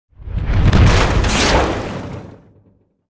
equip blowing off.wav